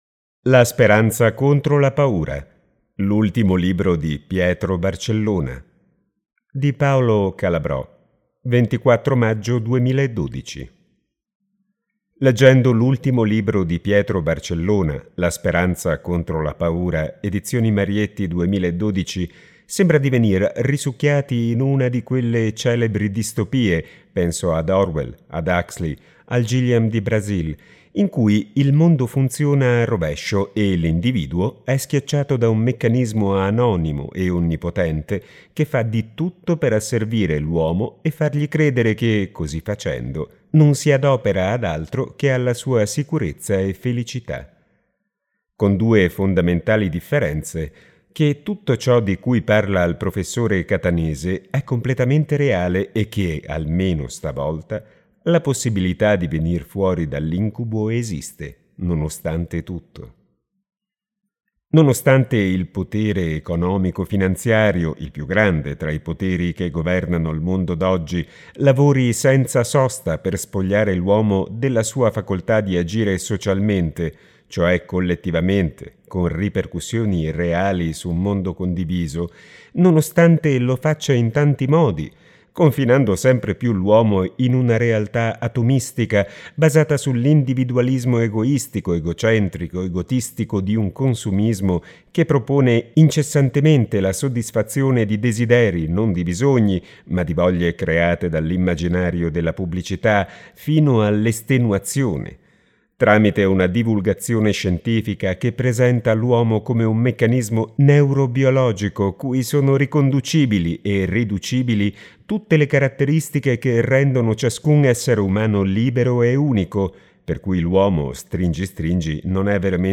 (voce di